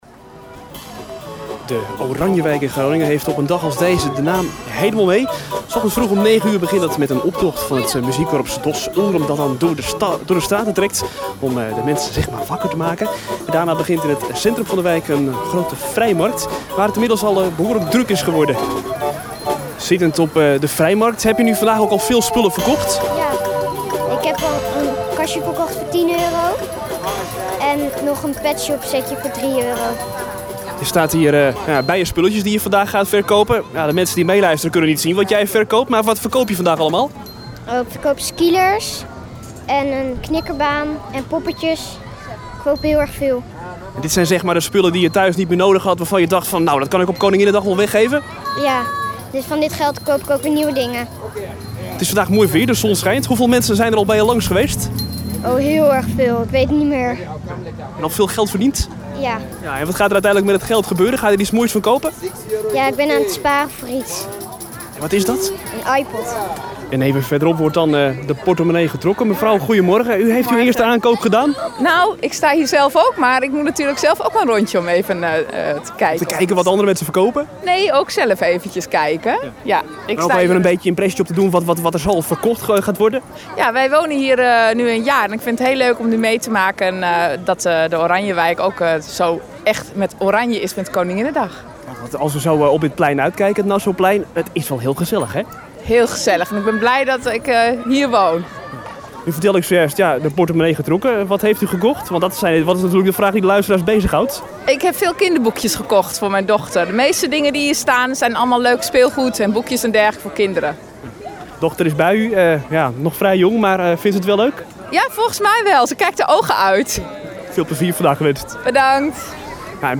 Een vrijmarkt, muziek maken, limonade en veel gezelligheid. Op het Nassauplein in de Oranjewijk is het traditioneel op Koninginnedag druk.